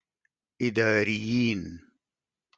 Sudanese Arabic